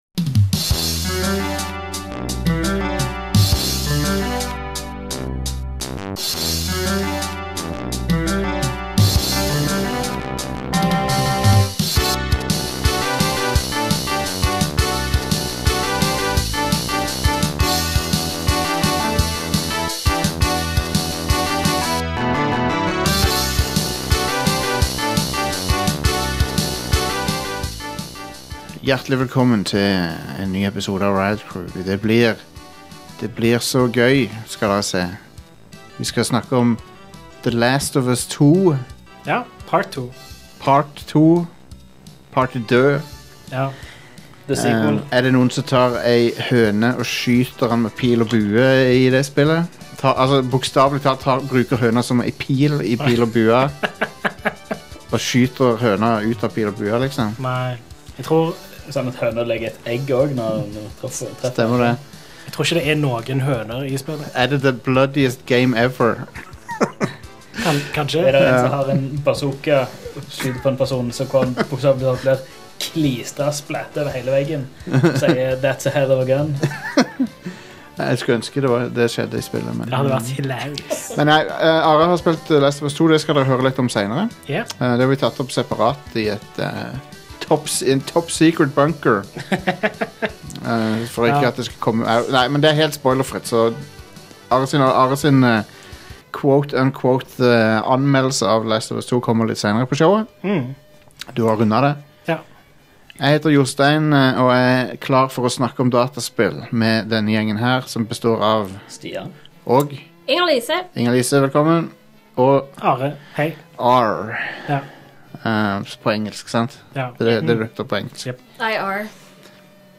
Over til noe mer lystig: Vi snakker også om glemte sportsserier i spill – noen som husker «Actua Soccer»? I den anledning har vi takket være lyttere klart å spore opp den norske versjonen av det spillet, med kommentarer av Arne Scheie som vi spiller mange klipp av denne uka.